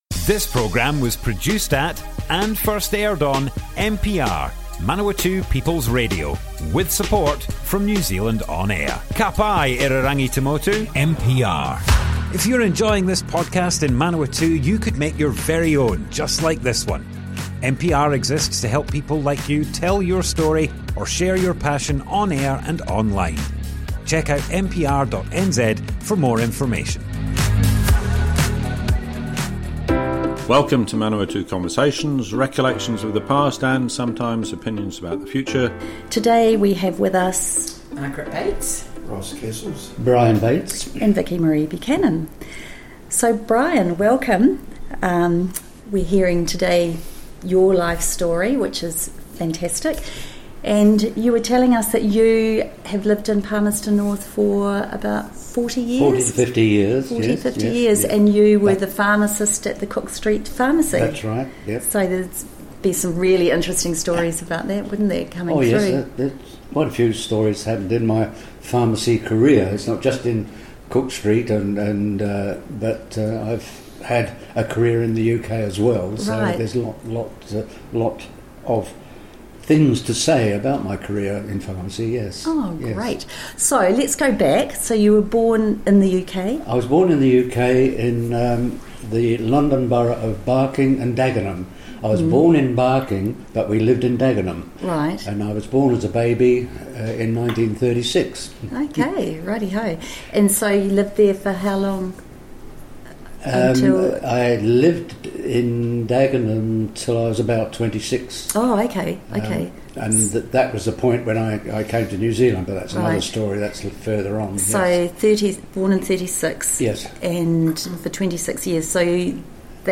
Broadcast on Manawatu People’s Radio, 24th October 2023.